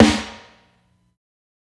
ILLMD013_SNARE_SAD.wav